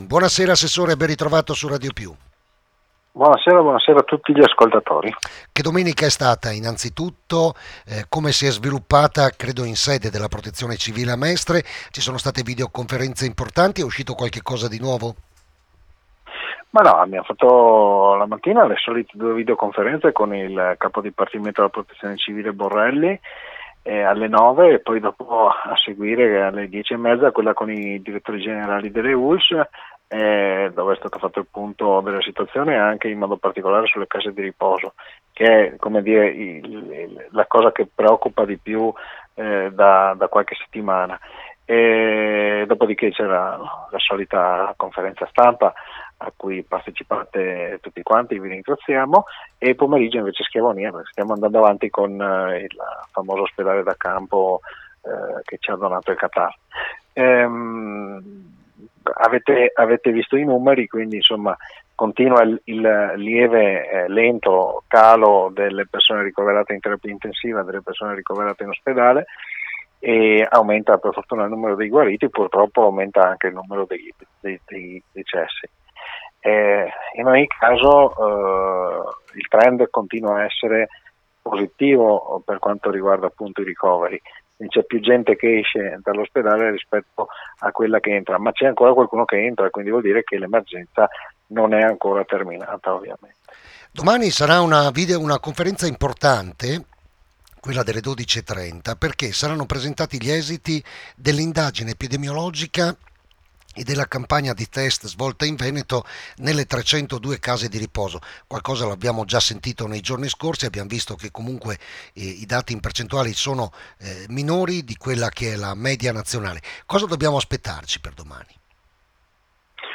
AGGIORNAMENTO ASSESSORATO PROTEZIONE CIVILE 19 APRILE 2020 L’INTERVENTO A RADIO PIU’ DI GIANPAOLO BOTTACIN, DAL GR DELLE 18:30 DI IERI